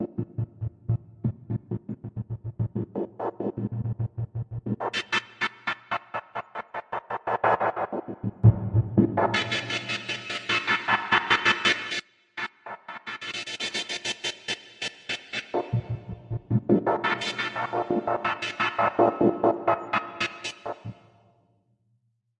描述：从报时钟的滴答声中发展出来的短小精巧的噪音事件的小场。
标签： 噪声 混响 重复
声道立体声